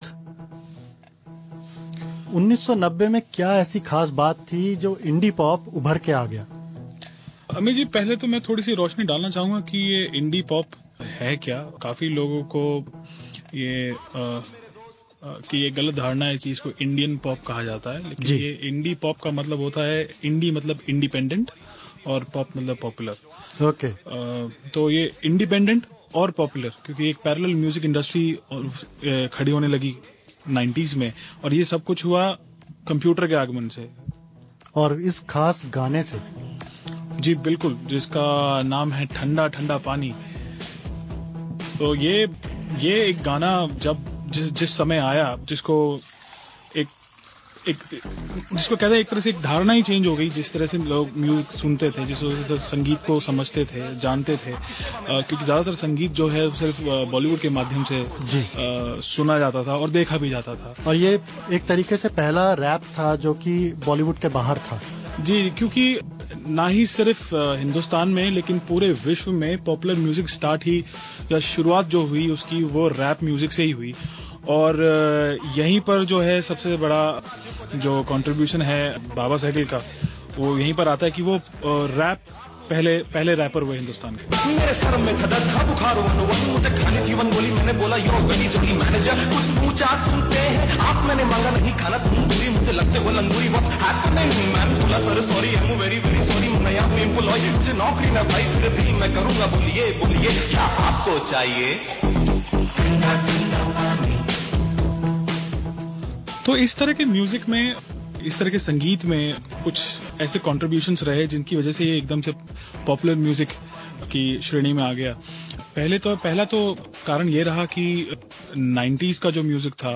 (conversation)